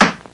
Hammer Sound Effect
Download a high-quality hammer sound effect.
hammer-1.mp3